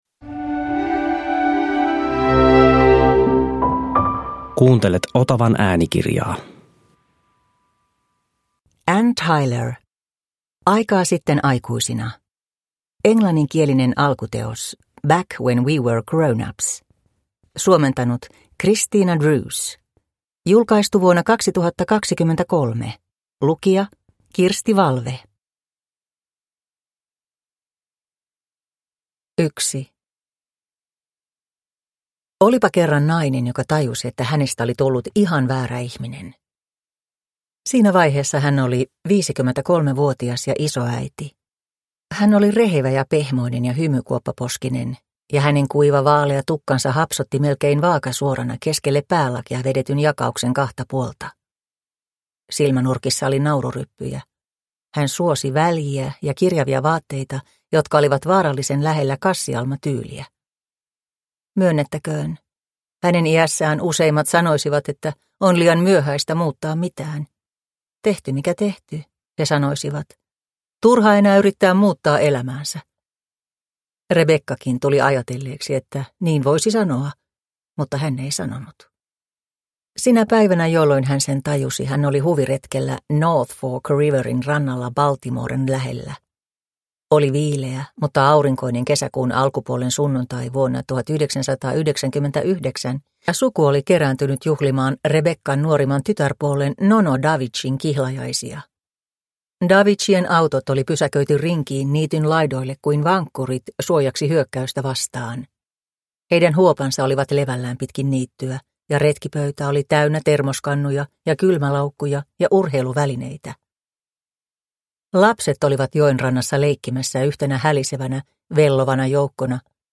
Aikaa sitten aikuisina – Ljudbok – Laddas ner